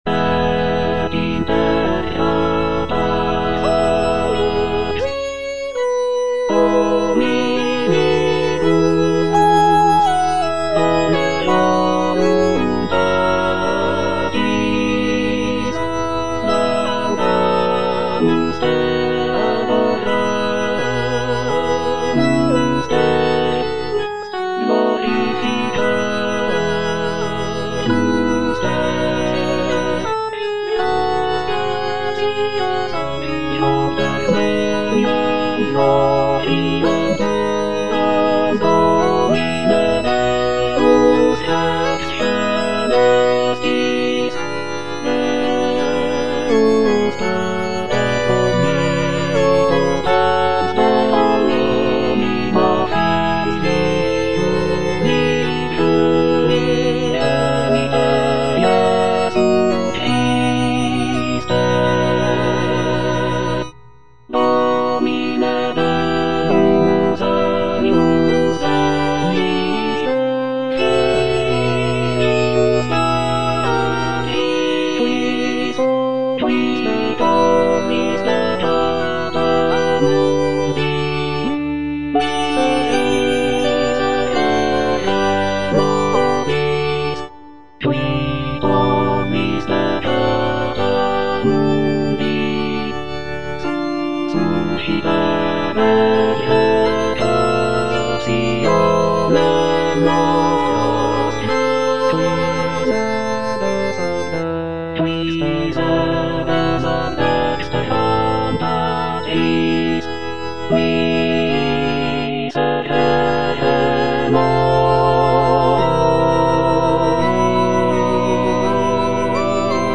J.G. RHEINBERGER - MISSA MISERICORDIAS DOMINI OP.192 Gloria (All voices) Ads stop: auto-stop